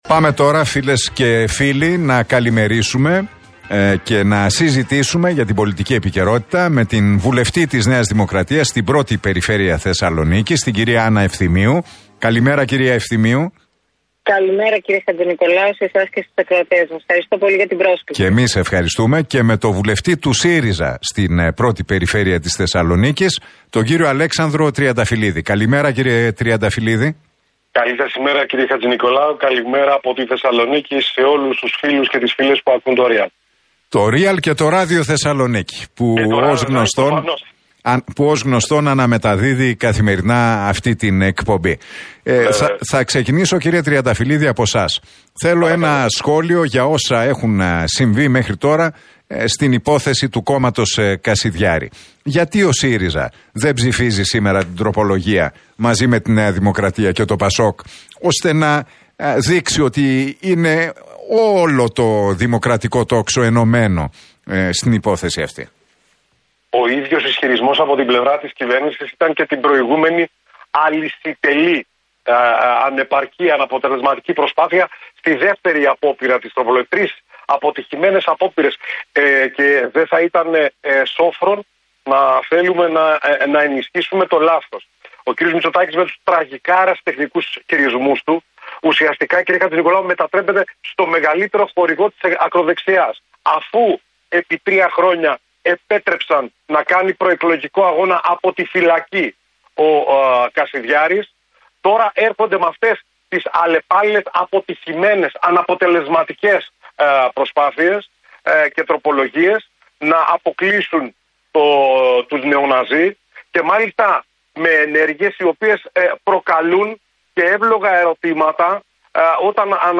Τα ξίφη τους για την οικονομία και τα εθνικά θέματα διασταύρωσαν στον αέρα του Realfm 97,8 σε ένα ραδιοφωνικό debate, στην εκπομπή του Νίκου Χατζηνικολάου οι βουλευτές Α Θεσσαλονίκης με τη ΝΔ και τον ΣΥΡΙΖΑ, Άννα Ευθυμίου και Αλέξανδρος Τριανταφυλλίδης.